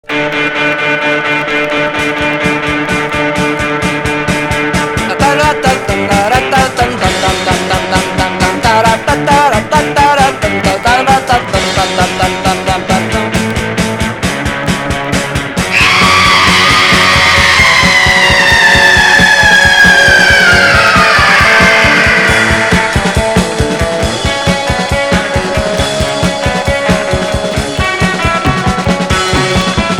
Pop psyché Quatrième 45t retour à l'accueil